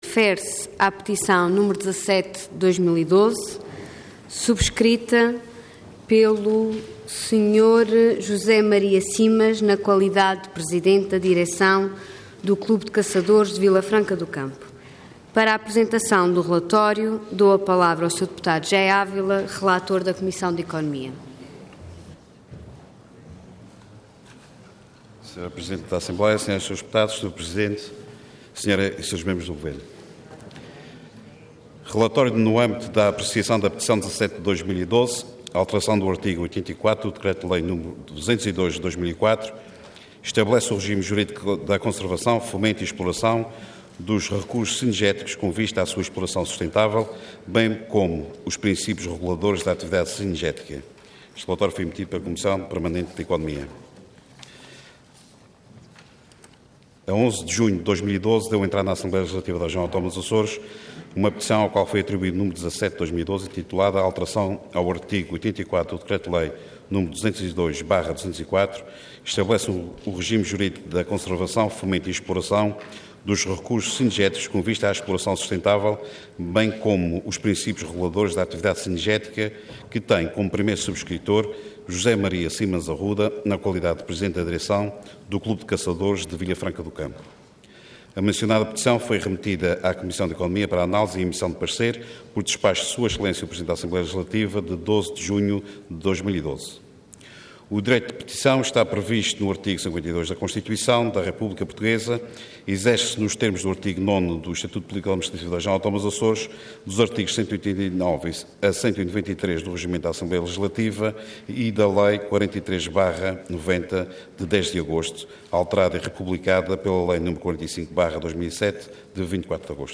Assembleia Legislativa da Região Autónoma dos Açores
Intervenção